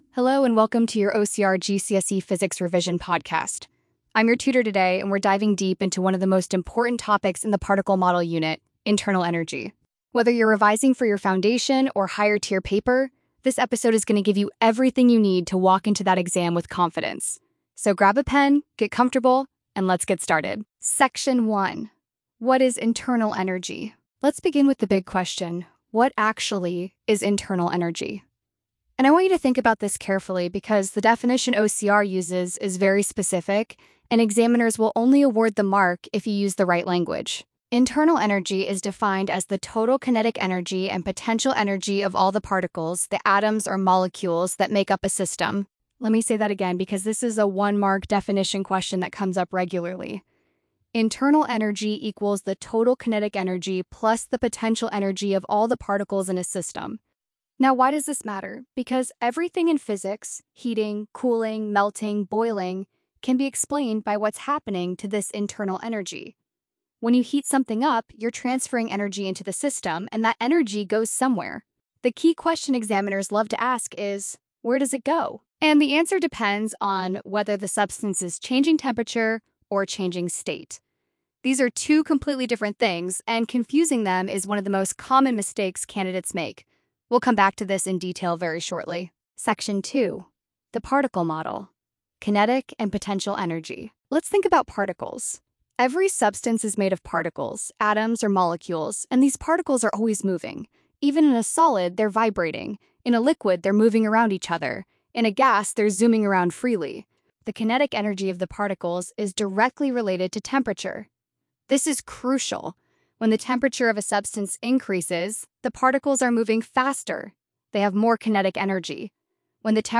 🎙 Podcast Episode Internal energy 13:38 0:00 -13:38 1x Show Transcript Study Notes Overview Internal energy is a fundamental concept in thermodynamics and the particle model of matter.